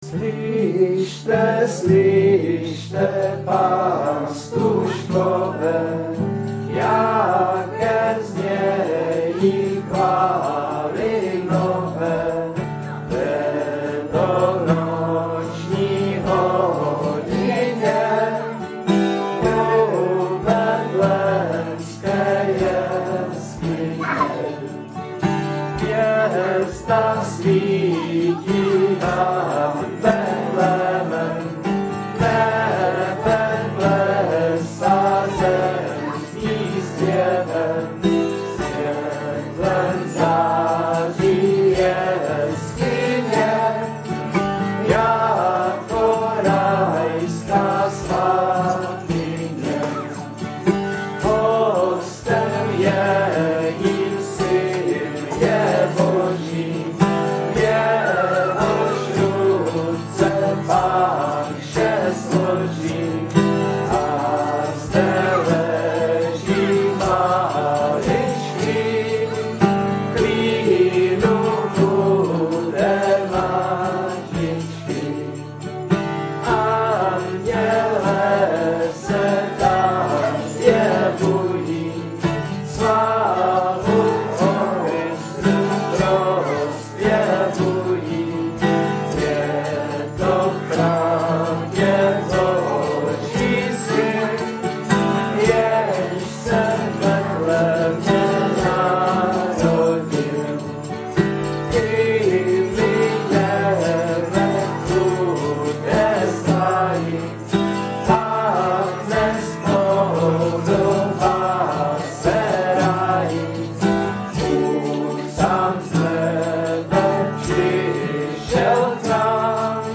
nahrávka ze zpívání